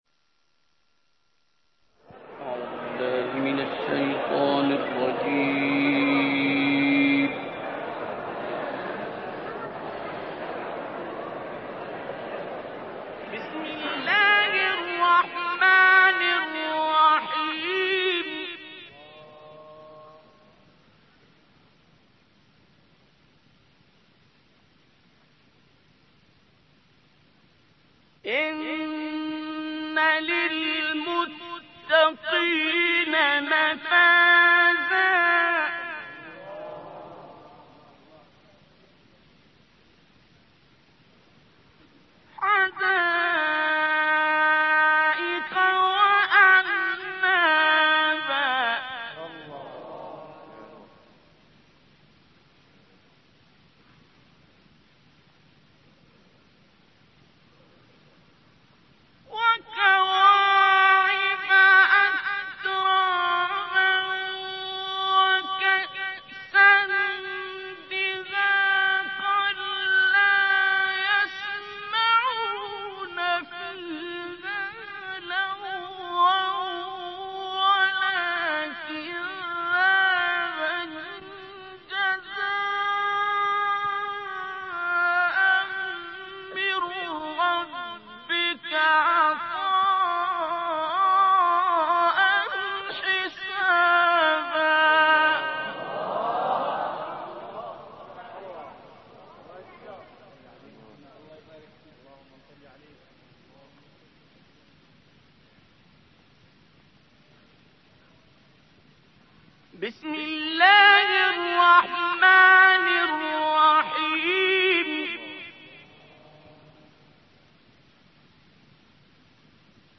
تلاوت زیبا و تاثیر گذار استاد عبدالباسط سوره مبارکه نبأ+صوتی+متن و ترجمه
تهران_الکوثر: در ادامه تلاوت بسیار زیبا و ماندگار استاد عبدالباسط عبدالصمد سوره مبارکه نبأ آیات 31 تا 40 را می شنوید.